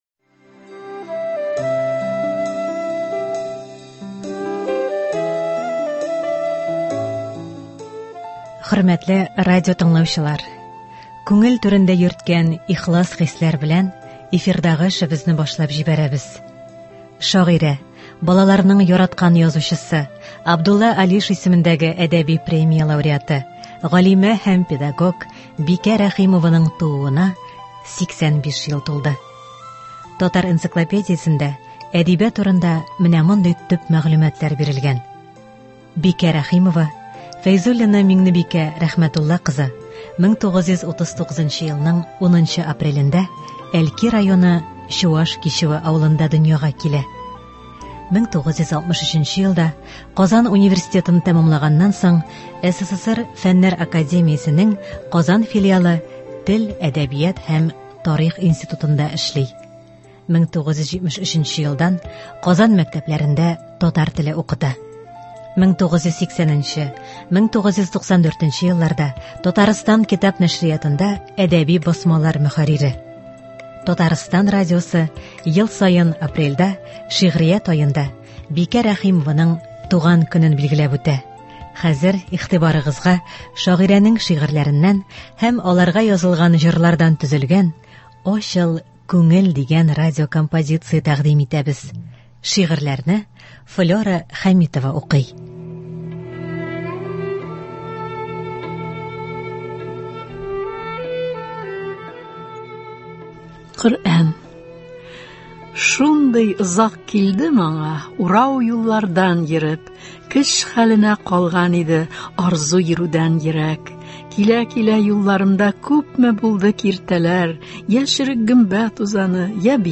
“Ачыл, күңел”. Бикә Рәхимова әсәрләреннән әдәби-музыкаль композиция.